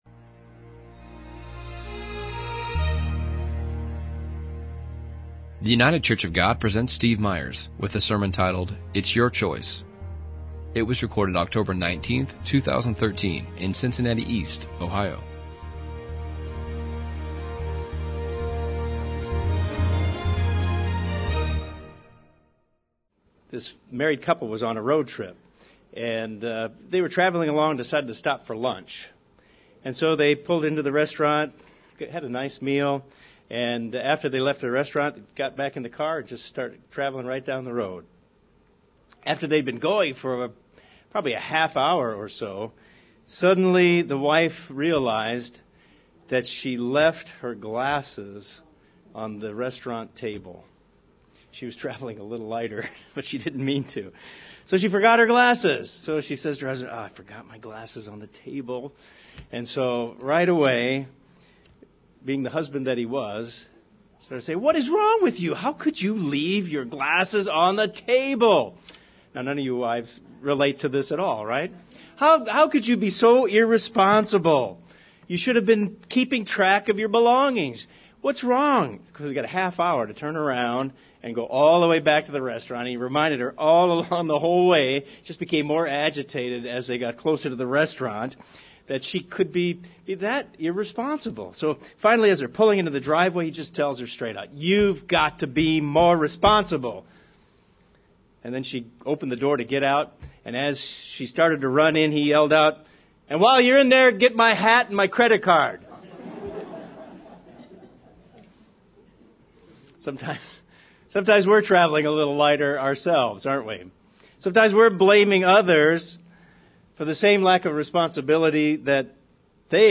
What does it mean to be a responsible person? In this sermon, we will examine what the Bible says about the concept of personal responsibility.